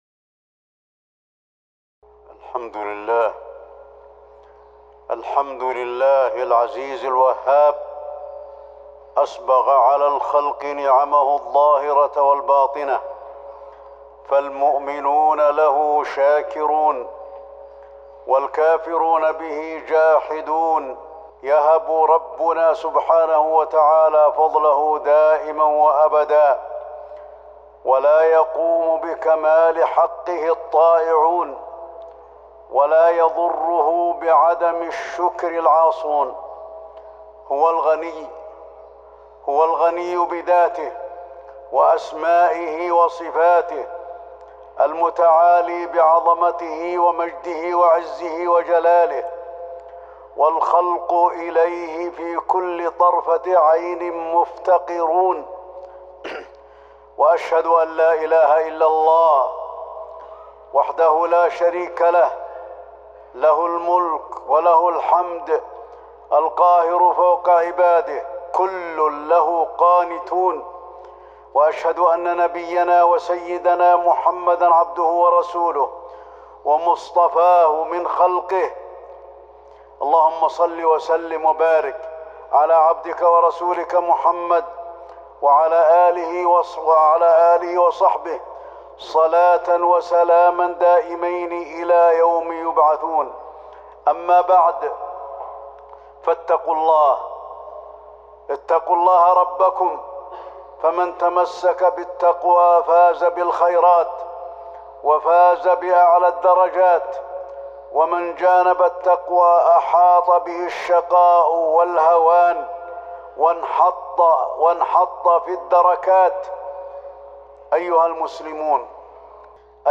تاريخ النشر ٢١ شعبان ١٤٤٠ هـ المكان: المسجد النبوي الشيخ: فضيلة الشيخ د. علي بن عبدالرحمن الحذيفي فضيلة الشيخ د. علي بن عبدالرحمن الحذيفي فضل الإحسان